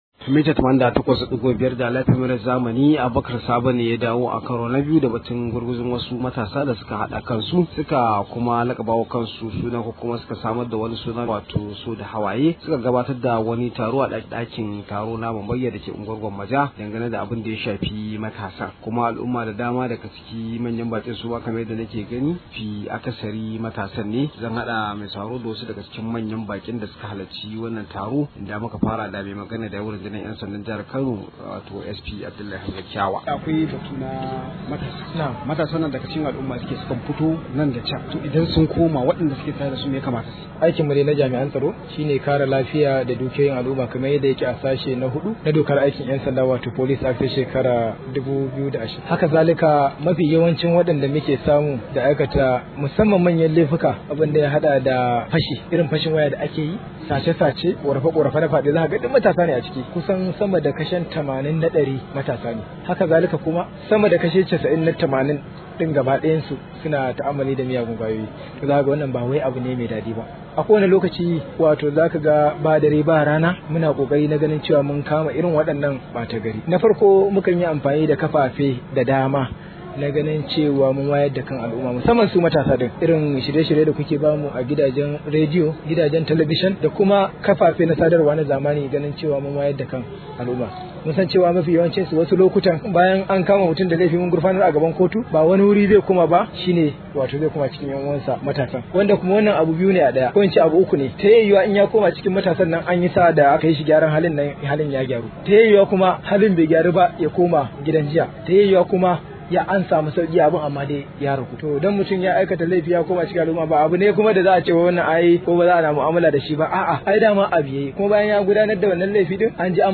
Rahoto